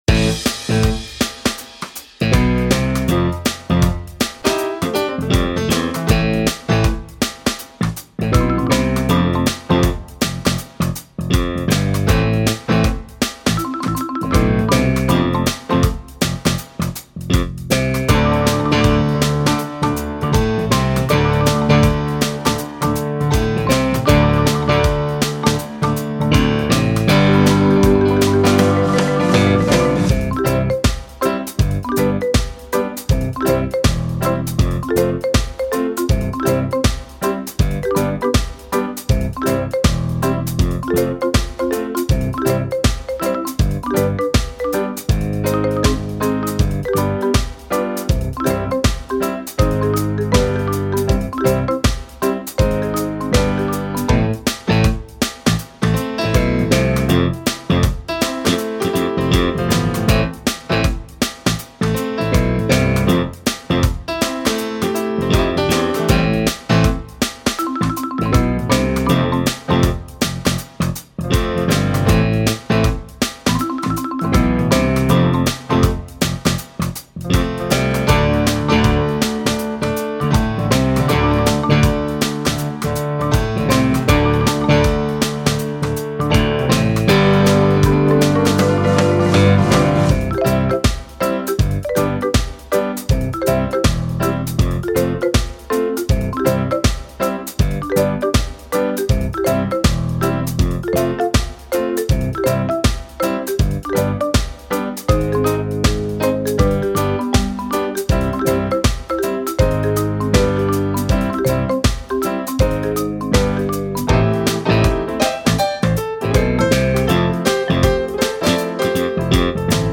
Übungsaufnahmen - Kaufen
Kaufen (Playback - kurz)